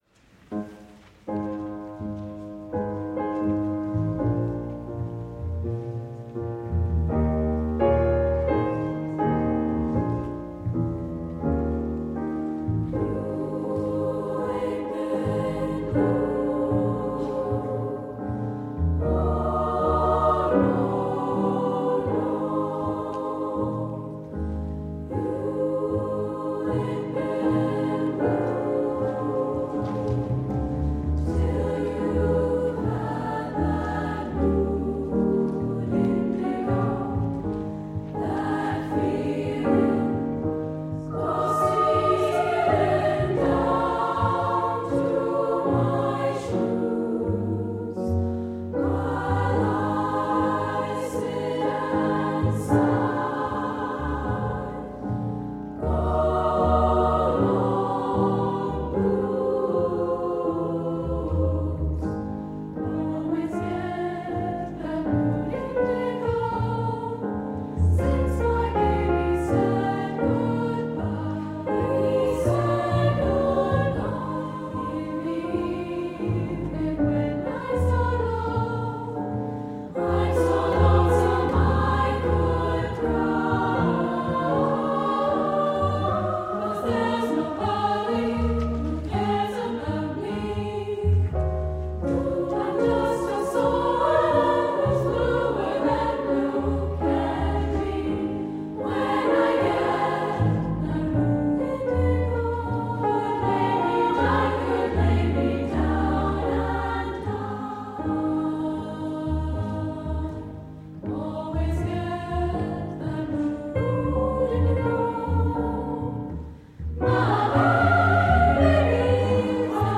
That melody is based on the official melody, embellishing it at places, and ignoring it completely at others.
Files The live recording from April 9th, 2010: mood-indigo-2010-04-09.mp3 .
The three parts sing independently, with staggered entrances, and each filling in gaps for the others. Here is how it looks in "piano roll" view, showing the interplay between the voices: The soprano is light blue; the mezzo-soprano is taupe; the alto is brown.
You can hear the soprano solo really clearly, though the alto is a bit buried.